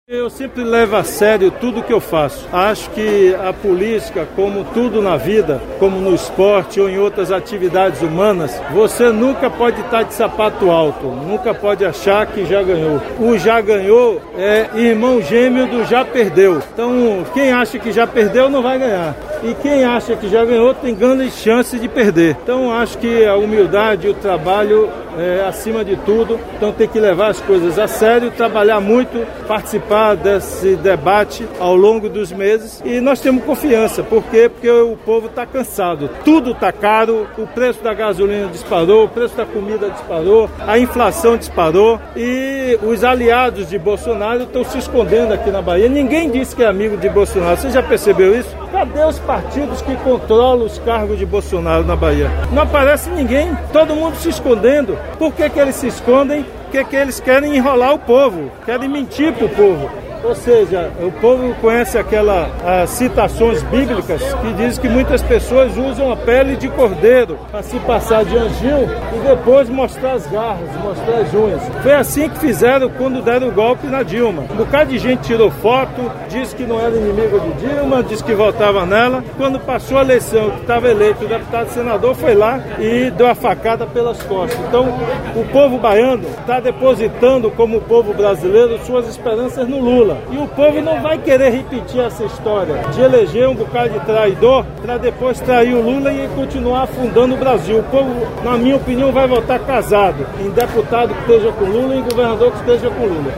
Também circula audio do governador comentando o assunto: